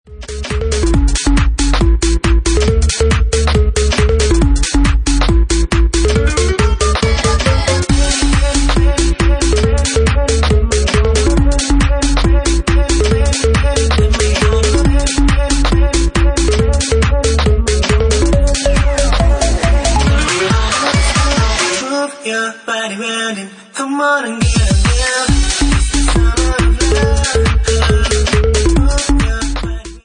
Bassline House at 138 bpm